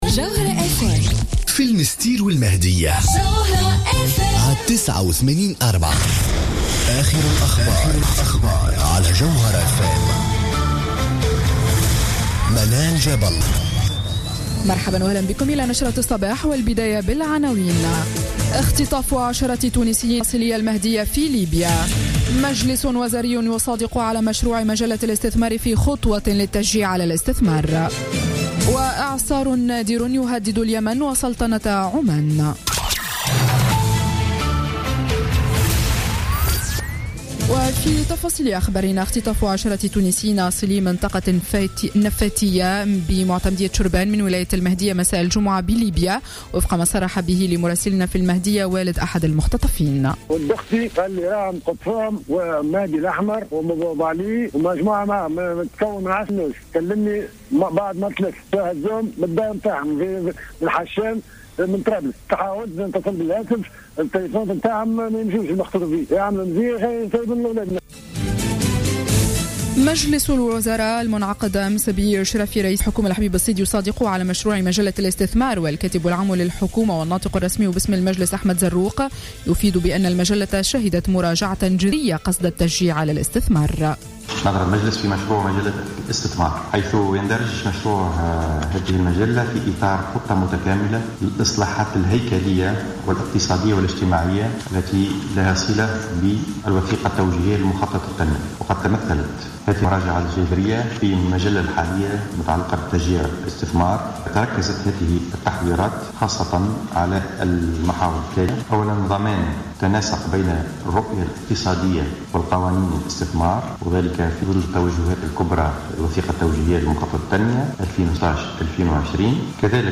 Journal Info 7h:00 du samedi 31 octobre 2015